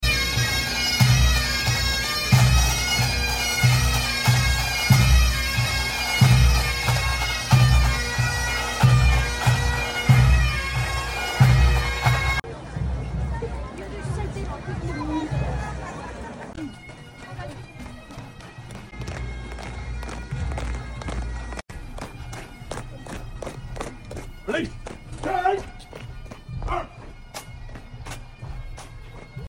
Famous Royal Parade In Windsor Sound Effects Free Download